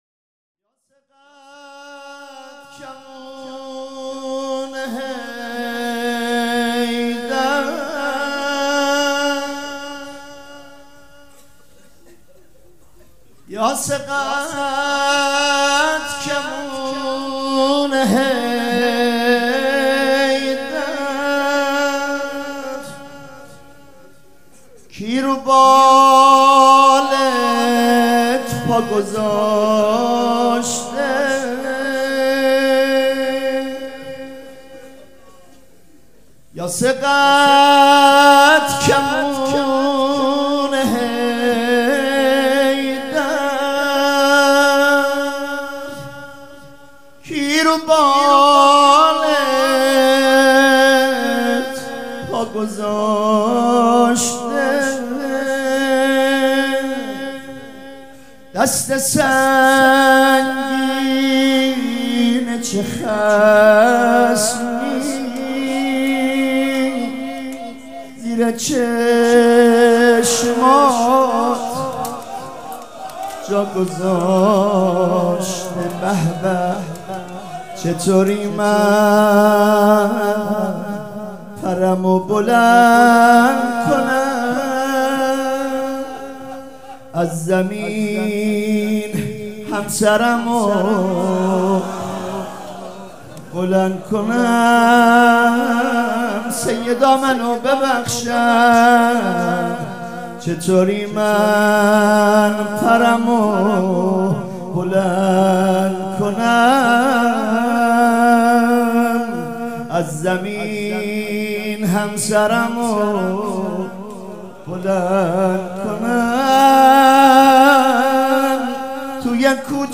روضه بخش سوم
شب اول فاطمیه ۹۶
سبک اثــر روضه